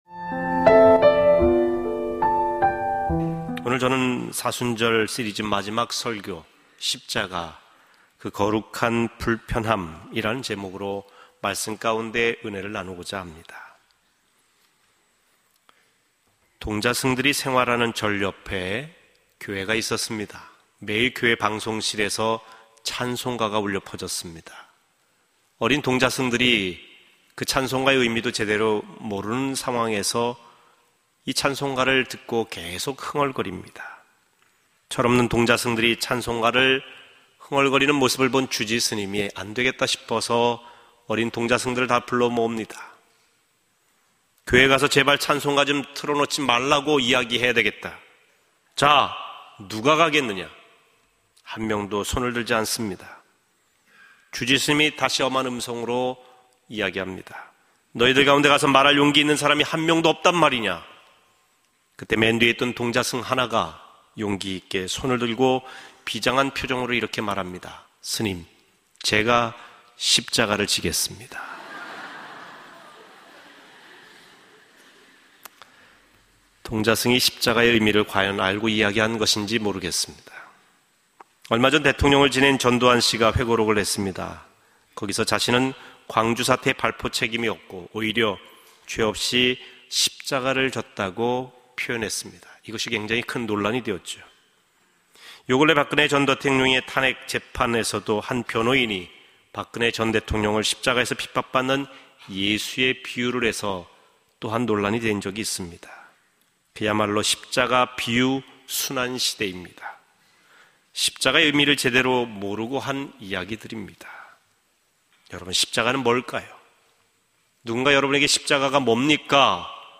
GMAN 라디오 설교방송